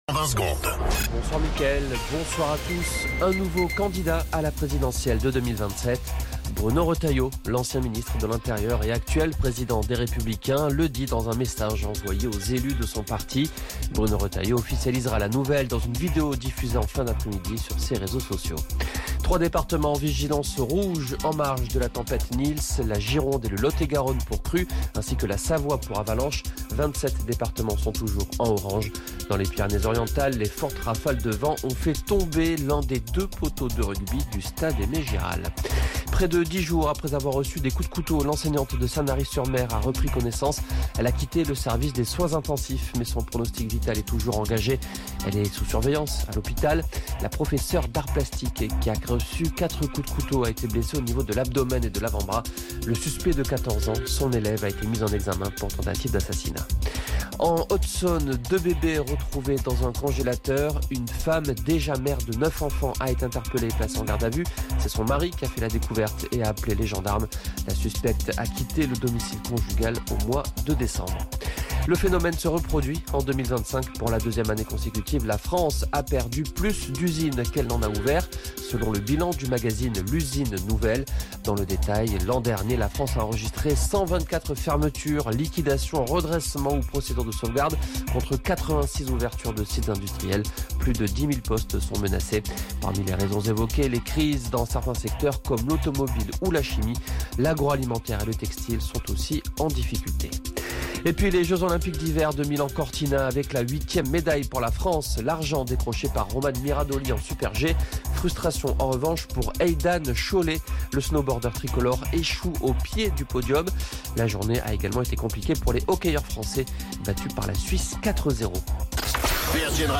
Flash Info National